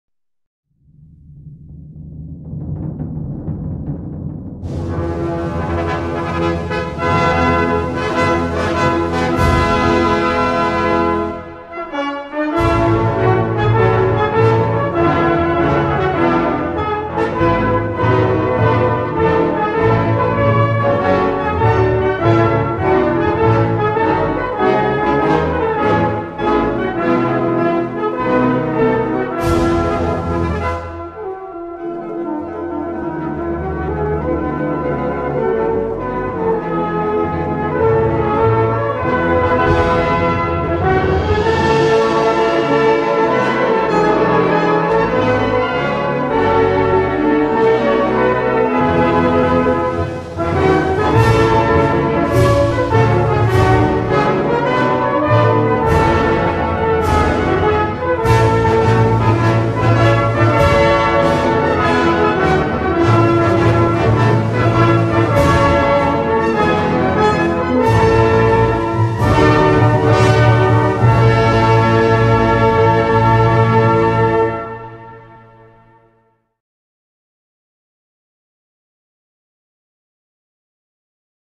хоровое исполнение